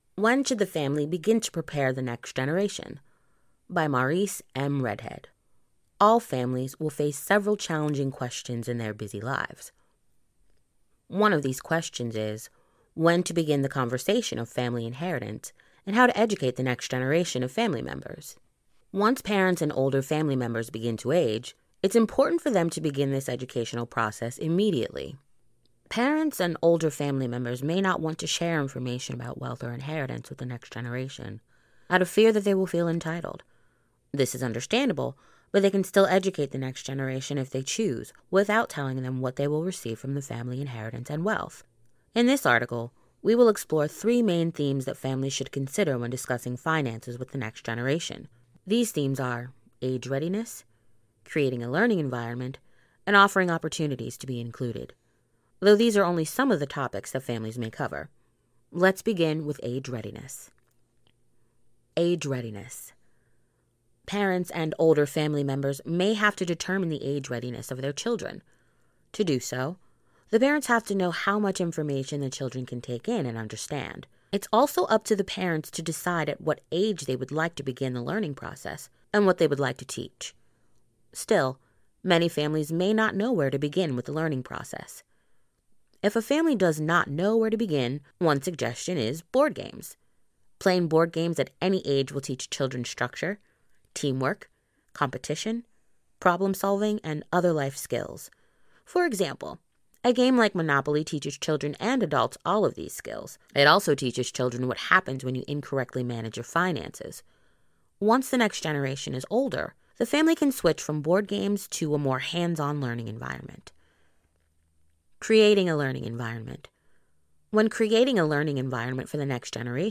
Voice Age
Young Adult
Middle Aged